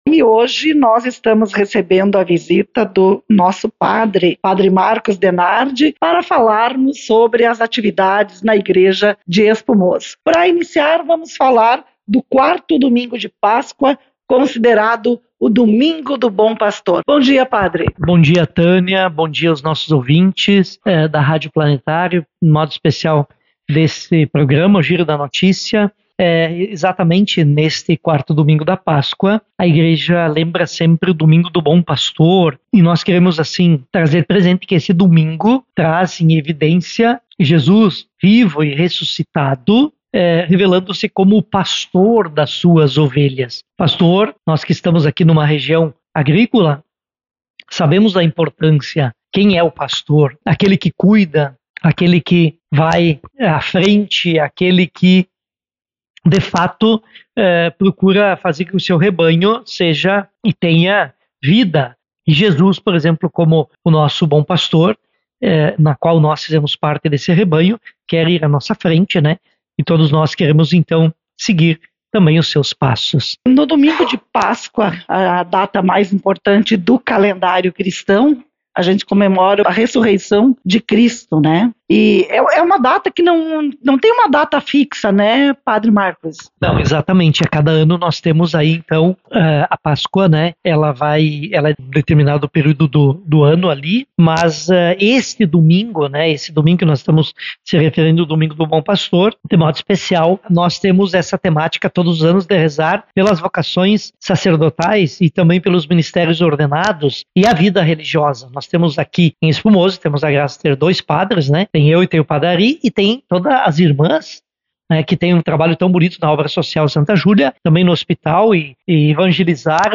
em entrevista exclusiva